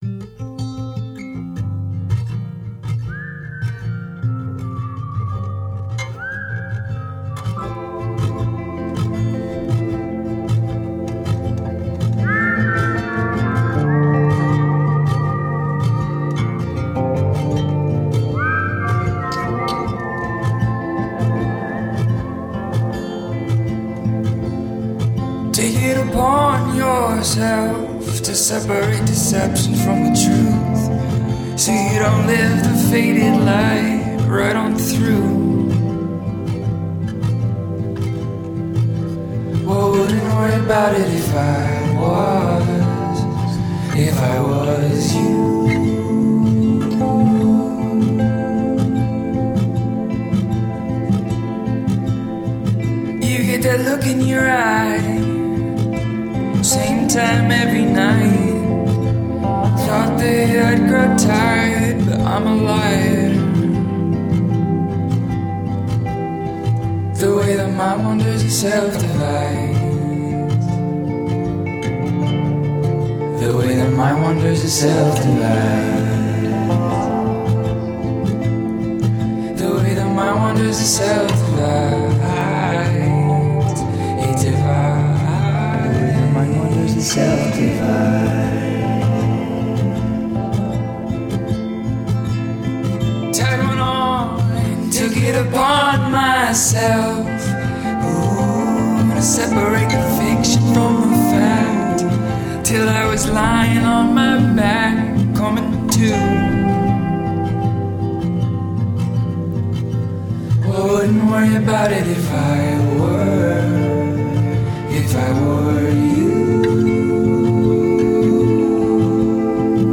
où il nous embarque avec sa folk envoûtante et aérienne.
chœurs